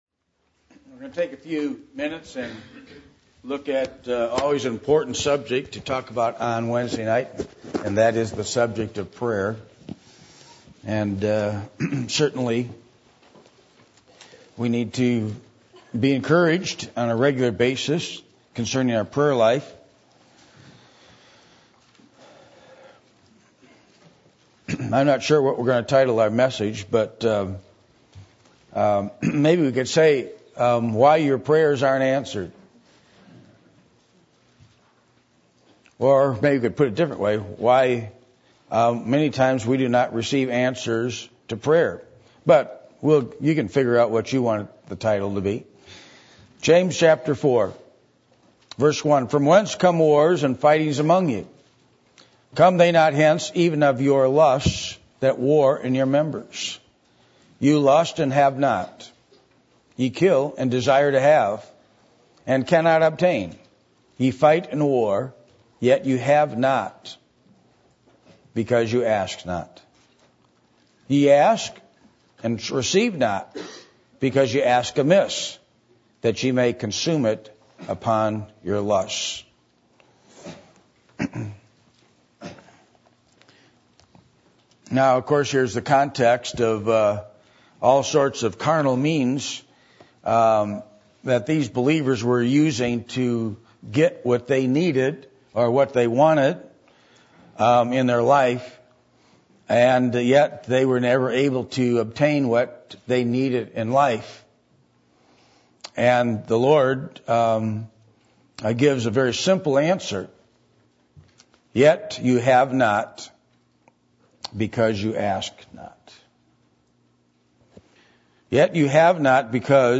James 4:1-3 Service Type: Midweek Meeting %todo_render% « Will You Serve The Lord?